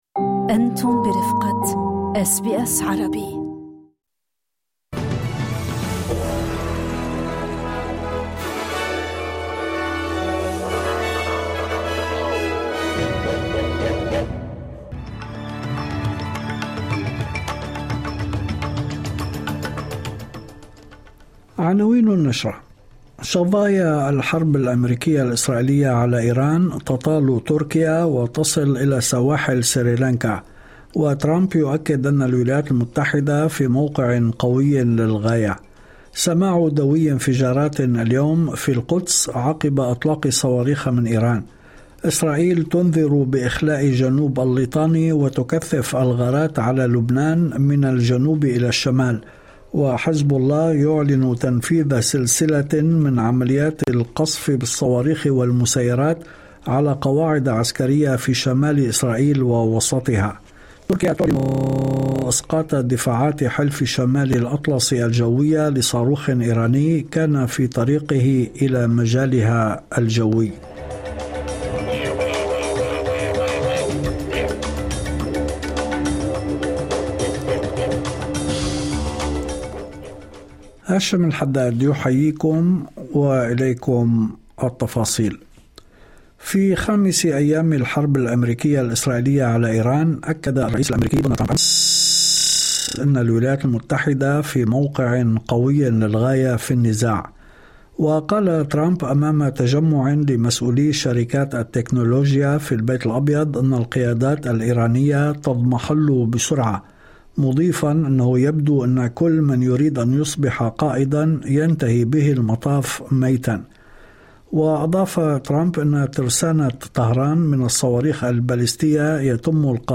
نشرة أخبار المساء 05/03/2026